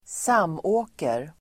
Ladda ner uttalet
Uttal: [²s'am:å:ker]